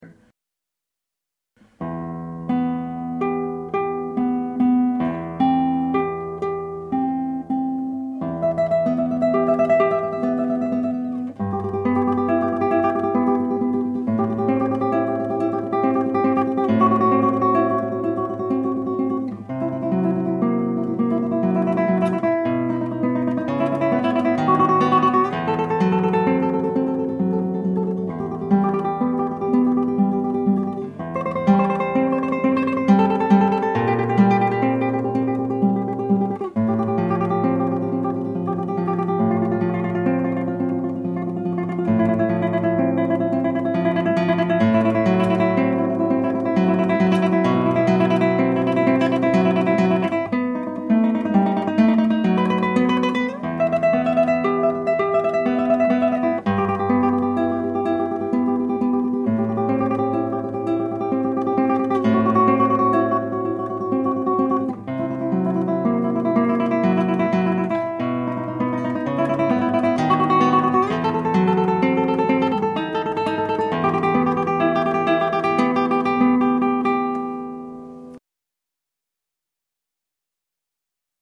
The trouble is that it’s not easy to make recordings that do justice either to player or instrument.
on this cedar top guitar
classical-guitar-2.mp3